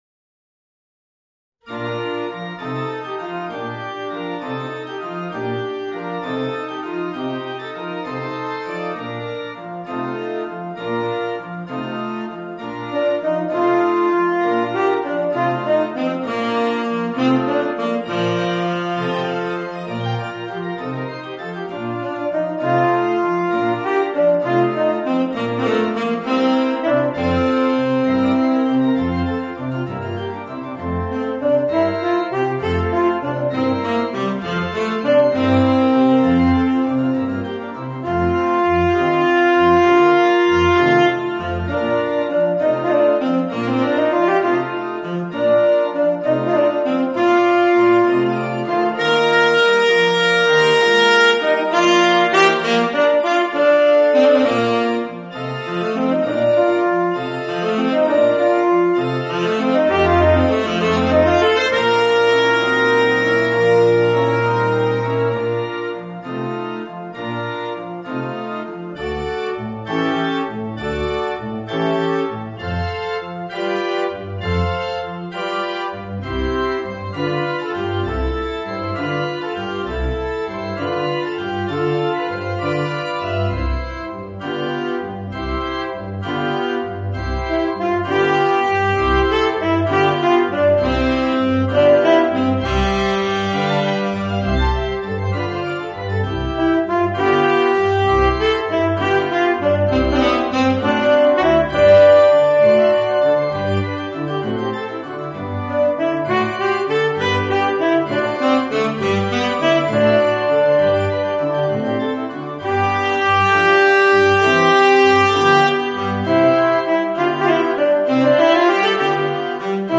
Voicing: Tenor Saxophone and Organ